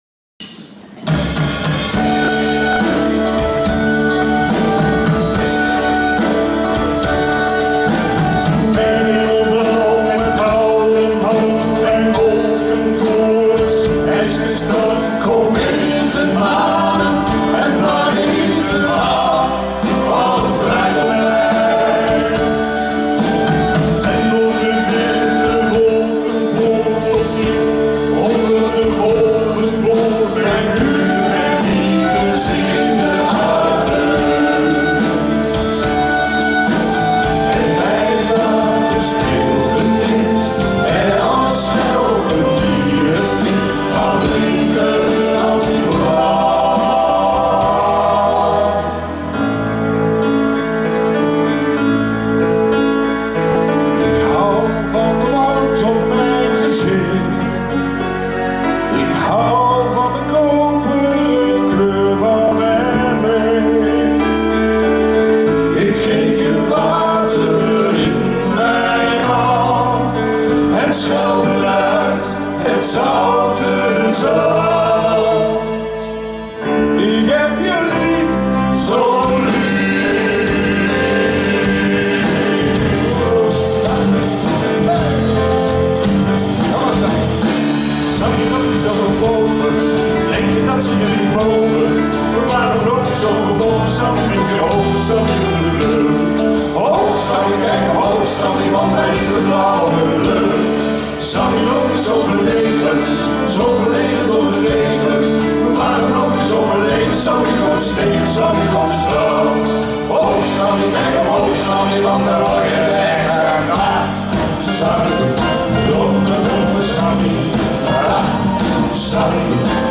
Zanggroep
Vijf mannen.